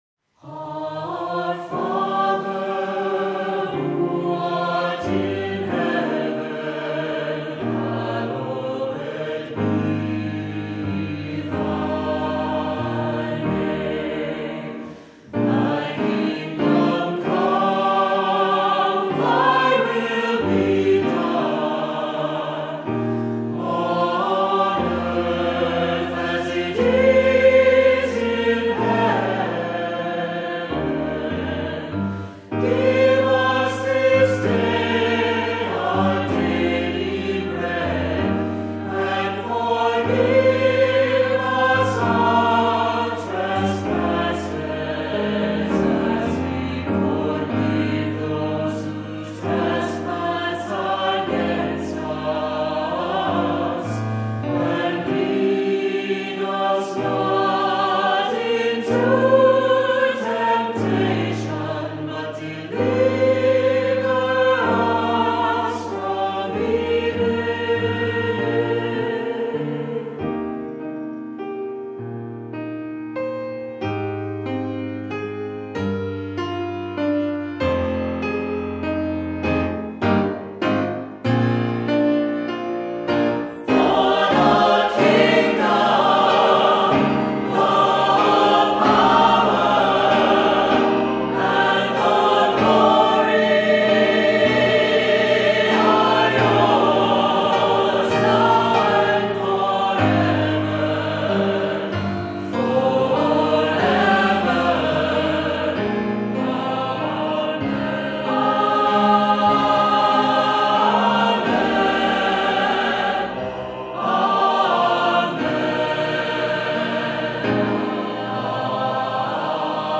Voicing: Unison; Solo; SATB; Assembly